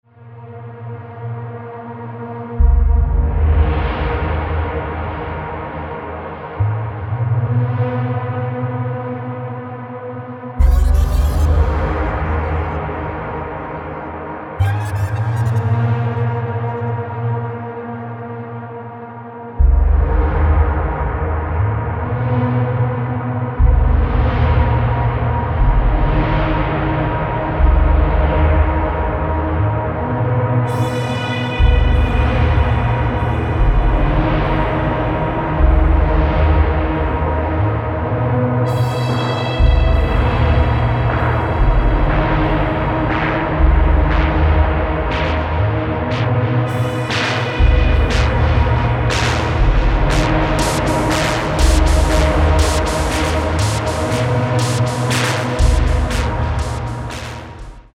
noise, dark ambient, hometaping